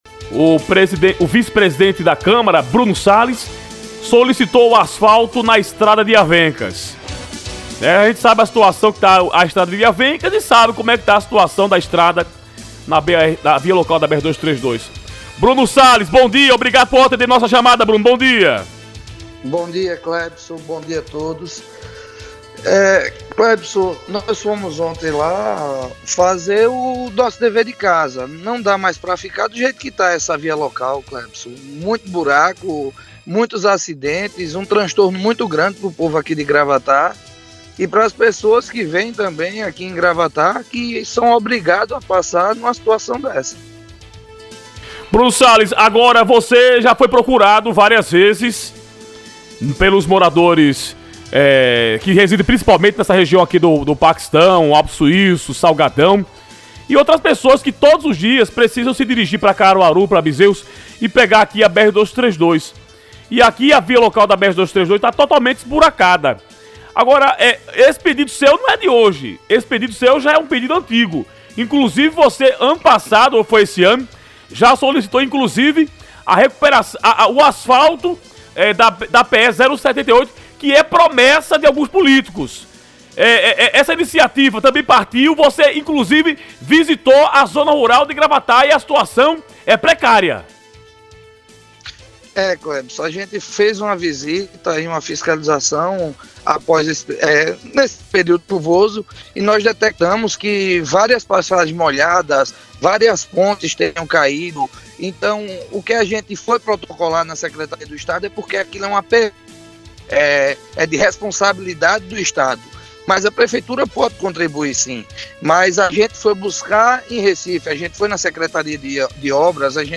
O vice-presidente da Câmara Municipal de Gravatá, Bruno Sales (PSDB) concedeu entrevista por telefone ao Jornal da 98, transmitido pelas rádios Clima, Amaraji e Chã Grande FM em 98.5 MHZ.
ENTREVISTA-BRUNO-SALES.mp3